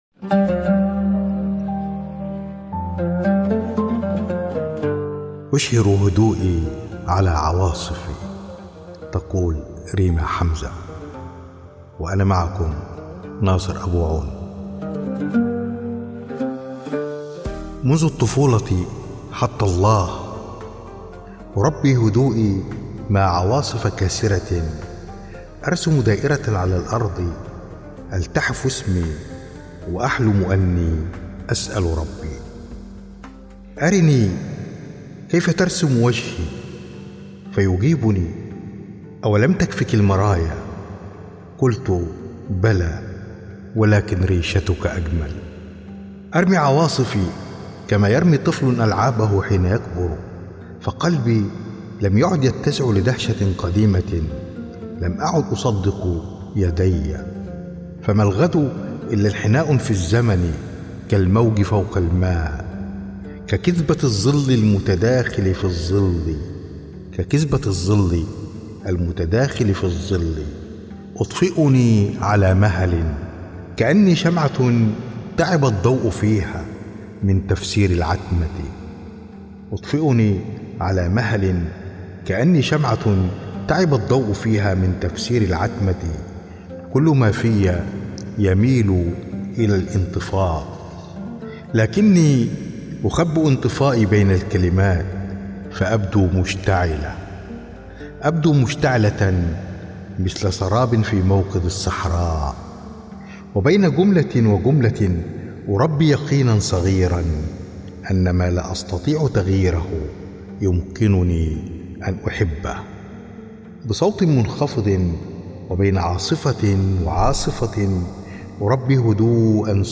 إلقاء